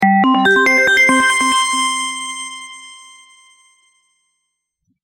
IDENTIDADE SONORA
Vinheta institucional de 5 segundos
Vinheta Institucional 5s